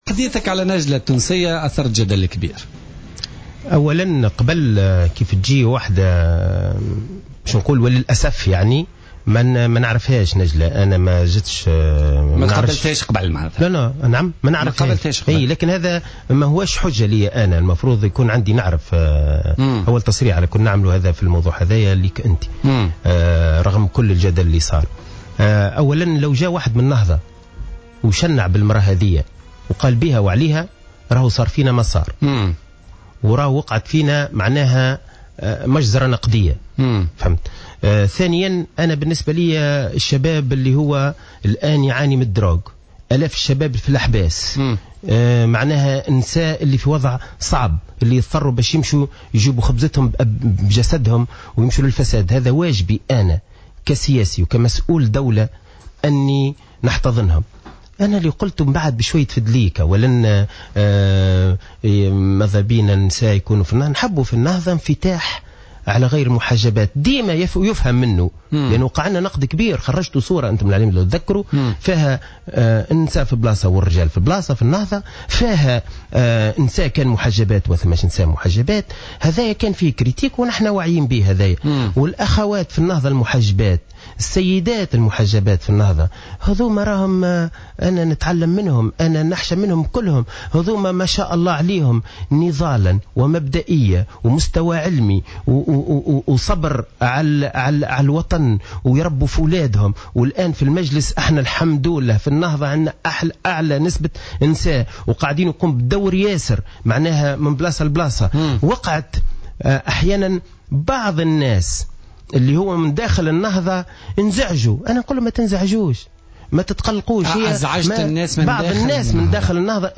وقال الجزيري ضيف برنامج "بوليتيكا" اليوم، إنه لو حدث العكس، وانتقدت النهضة هذه المغنية لتعرضت الحركة إلى هجوم وإلى "مجزرة نقدية"، وفق تعبيره.